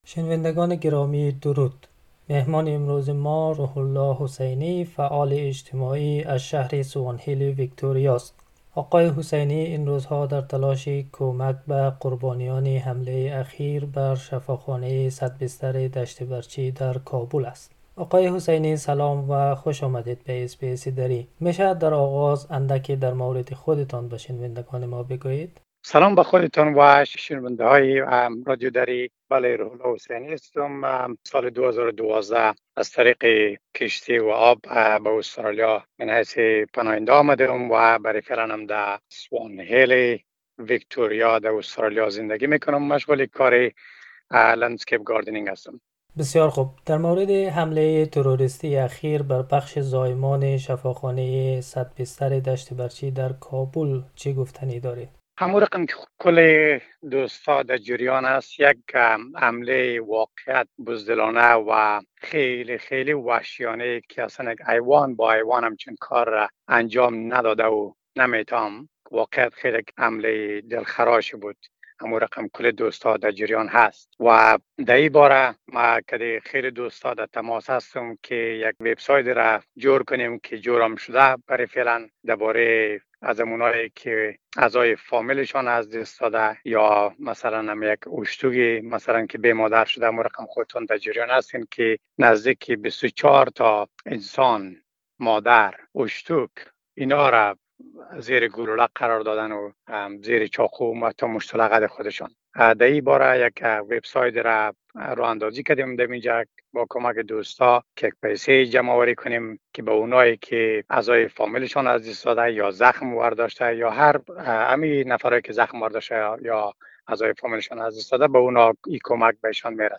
The interview is in the Dari language